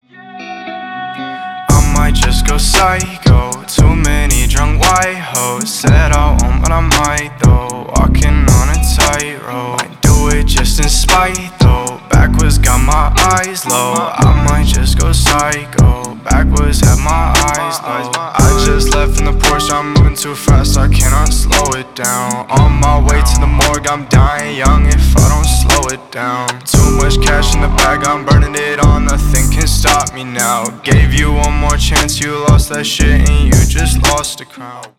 Ремикс
Рэп и Хип Хоп